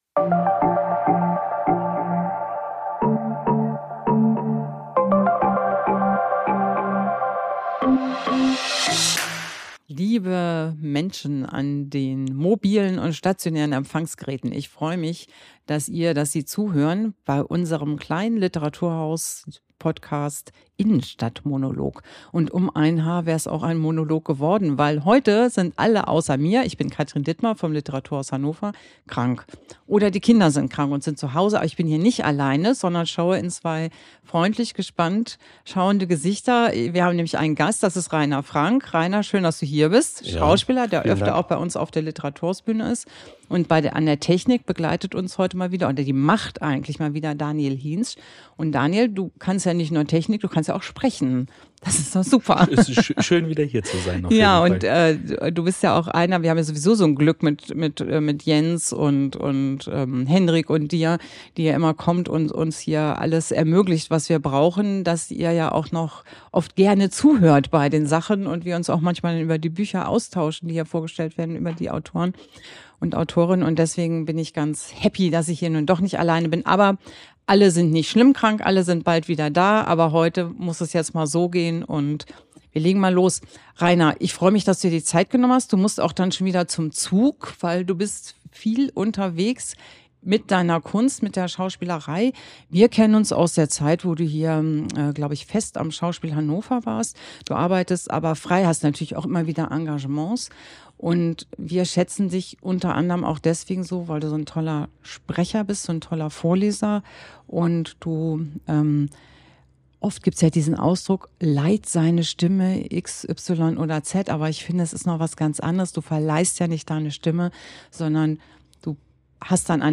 Doch zum Glück saß sie nicht allein vorm Mikro!